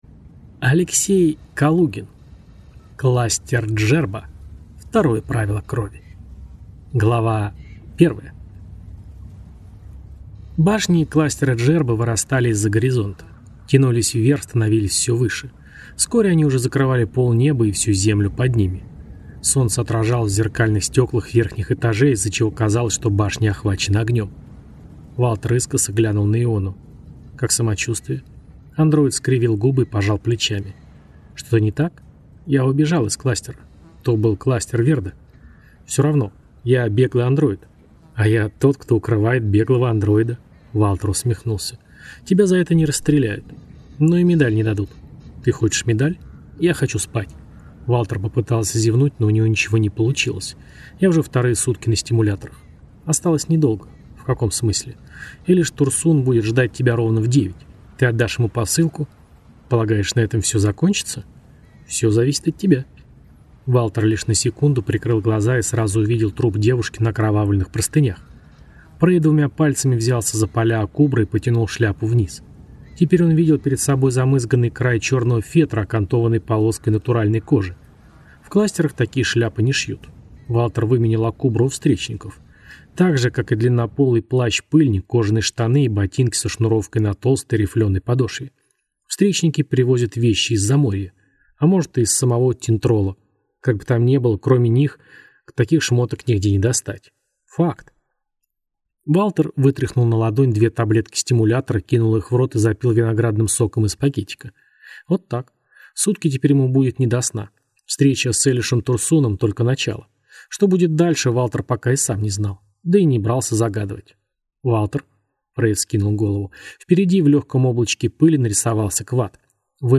Аудиокнига Кластер Джерба: Второе правило крови | Библиотека аудиокниг
Прослушать и бесплатно скачать фрагмент аудиокниги